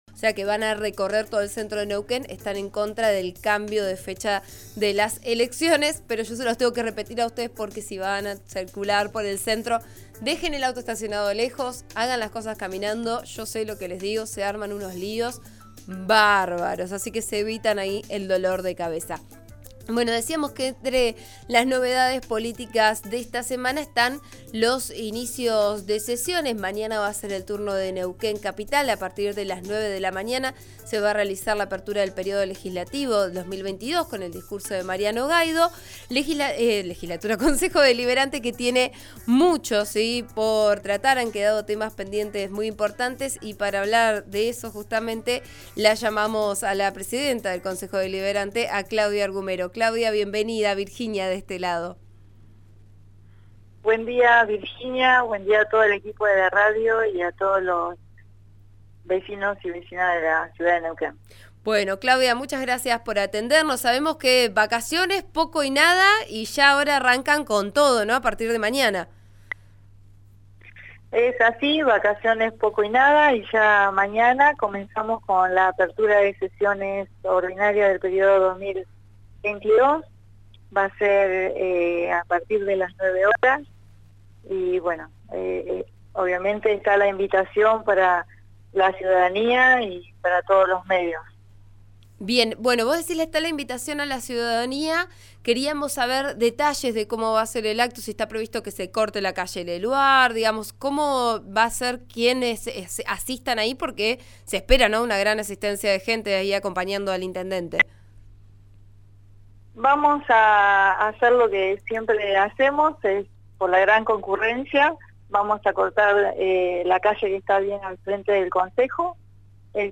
Escuchá la entrevista a la concejala y presidenta del Concejo Deliberante de Neuquén Claudia Argumero en «Vos a Diario» por RN RADIO: